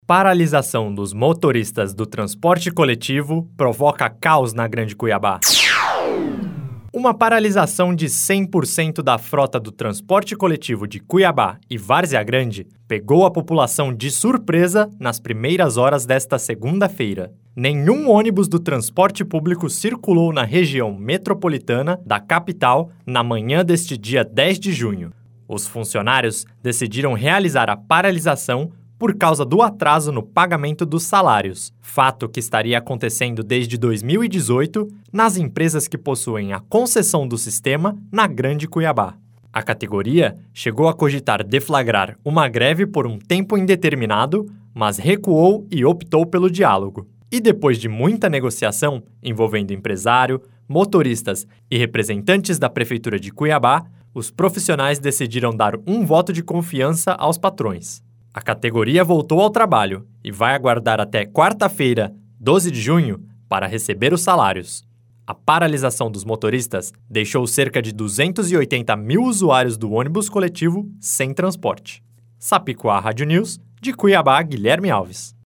Boletins de MT 11 jun, 2019